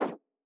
mod-select-overlay-pop-out.wav